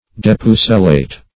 Search Result for " depucelate" : The Collaborative International Dictionary of English v.0.48: Depucelate \De*pu"ce*late\, v. t. [L. de + LL. pucella virgin, F. pucelle: cf. F. d['e]puceler.]